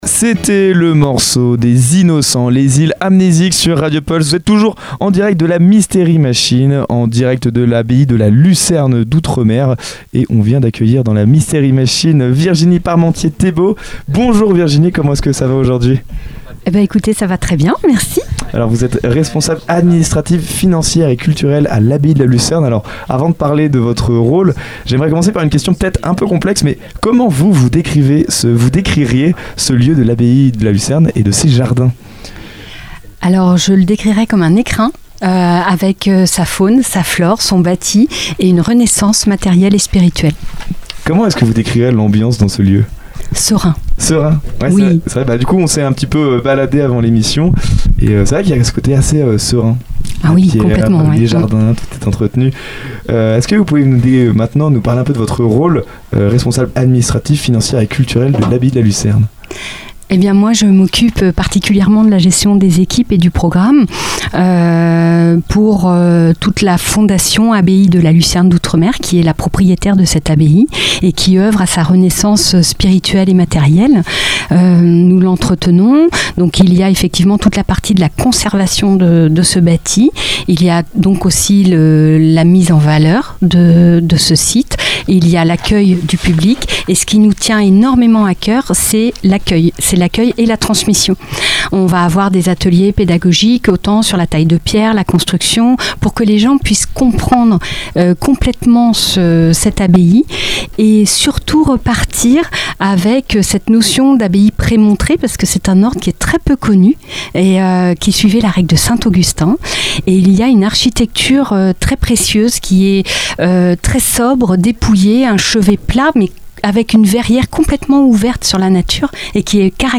Une interview passionnante qui met en lumière un patrimoine normand précieux, entre mémoire, culture et valorisation du territoire.